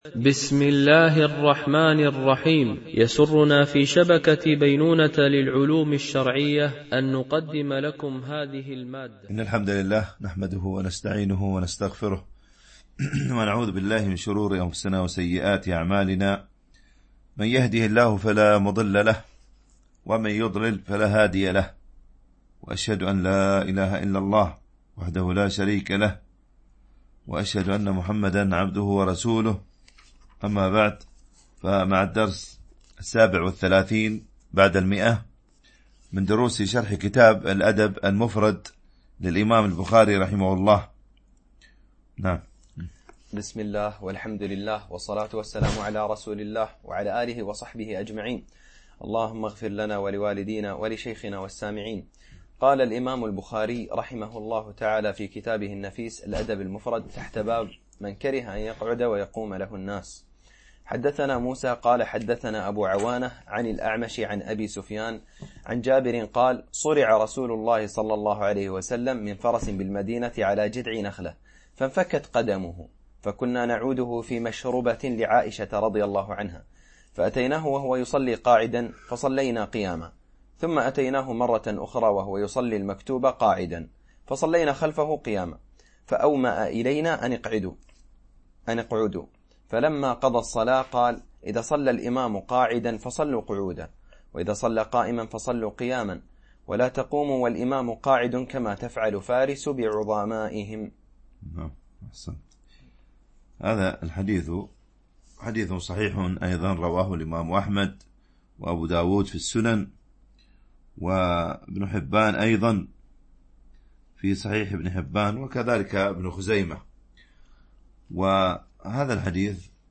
شرح الأدب المفرد للبخاري ـ الدرس 137 ( الحديث 960 - 965 )
MP3 Mono 22kHz 32Kbps (CBR)